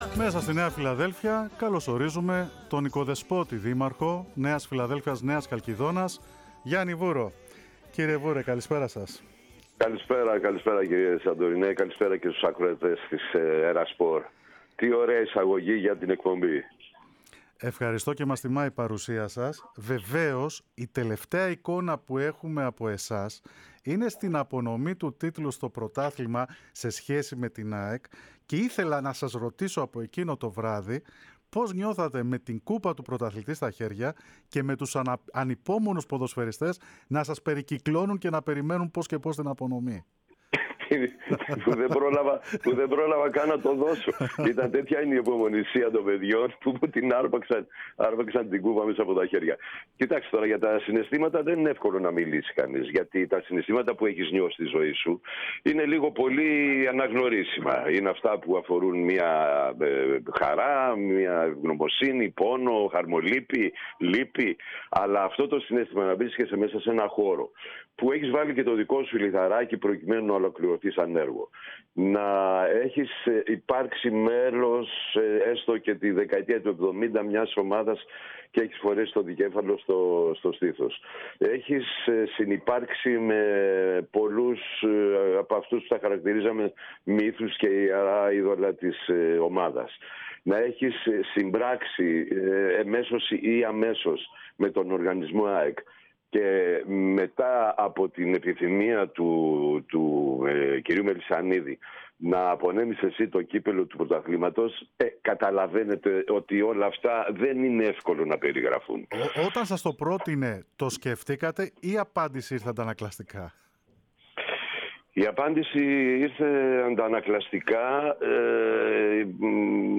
O Δήμαρχος Νέας Φιλαδέλφειας-Νέας Χαλκηδόνας μίλησε στο κορυφαίο αθλητικό ραδιόφωνο της χώρας, στο πλαίσιο του αφιερώματος για την εντυπωσιακή πορεία της “ένωσης” φέτος..